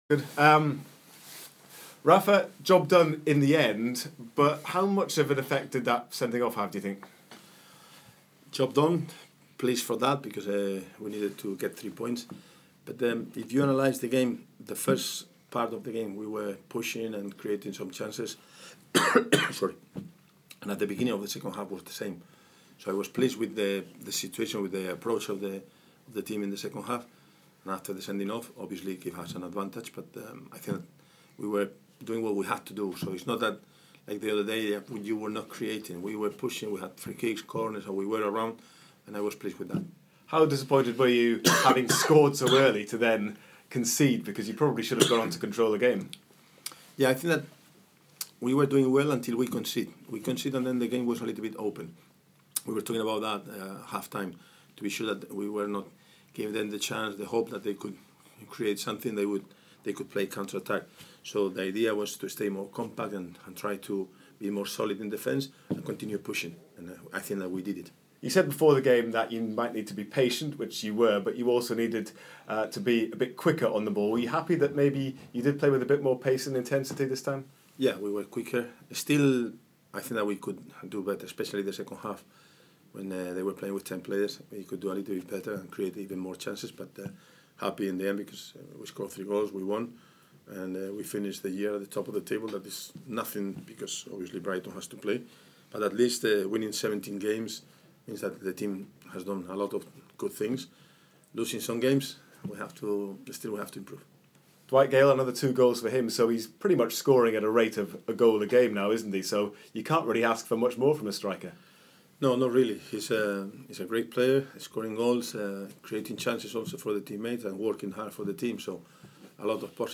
Rafa Benítez spoke to BBC Newcastle after United beat Nottingham Forest to end 2016 on top of the Championship.